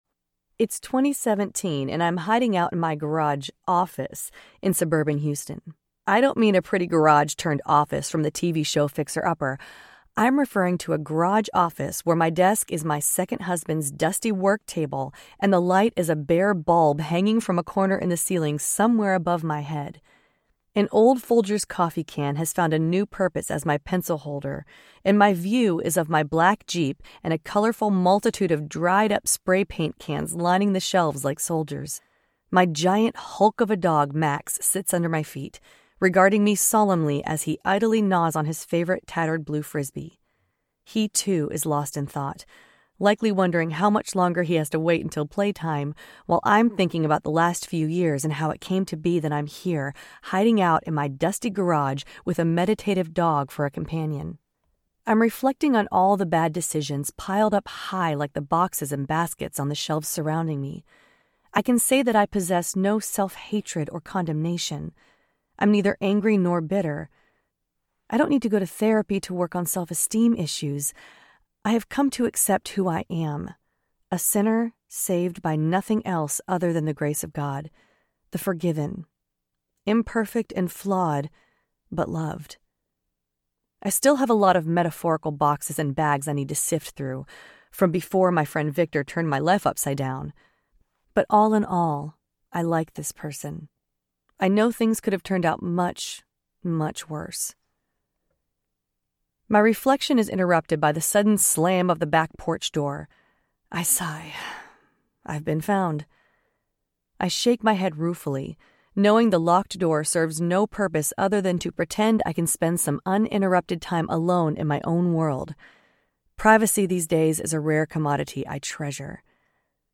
Kinda Like Grace Audiobook
Narrator
5.15 Hrs. – Unabridged